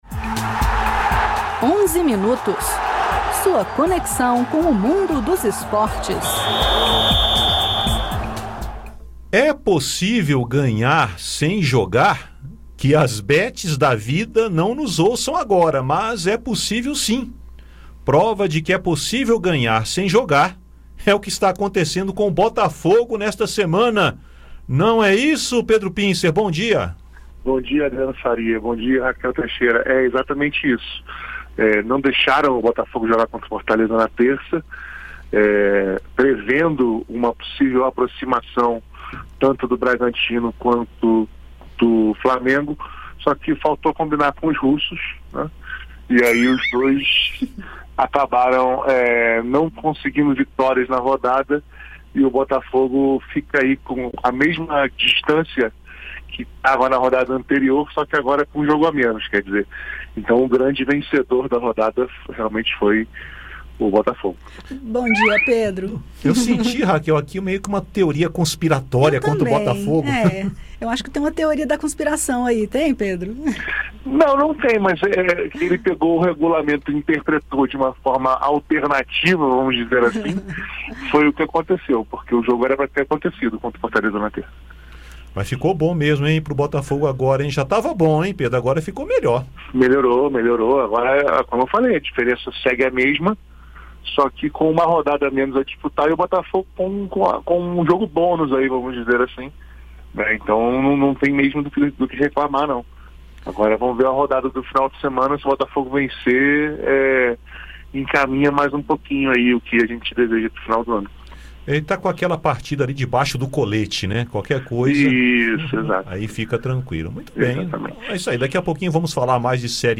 Um dos temas é relacionado ao doping. A Comissão de Esporte do Senado debateu esta semana casos acidentais de doping, e como evita-los. Os comentaristas também falam do Campeonato Brasileiro e dos Jogos Pan-americanos.